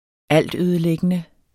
Udtale [ ˈalˀdˌøːðəlεgənə ]